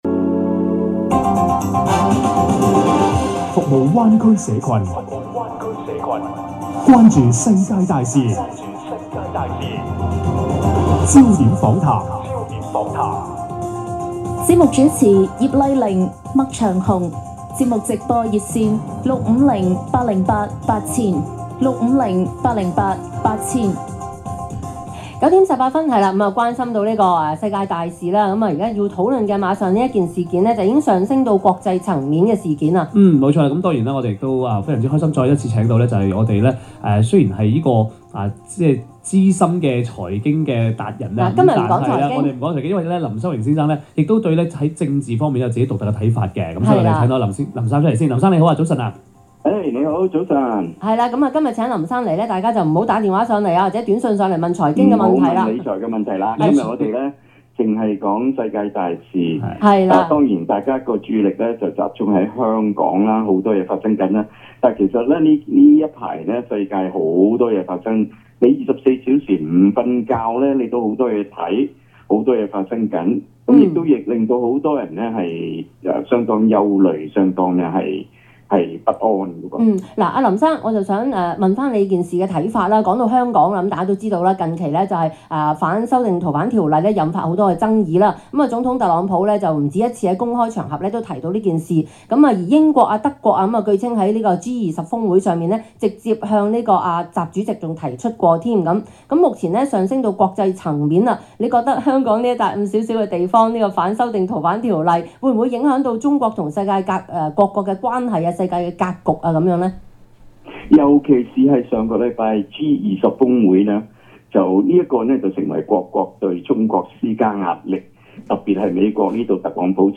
今天（七月三日）早上我在星島中文電台「焦點訪談」節目講到香港的情況，錄音已經取走廣告，歡迎收聽。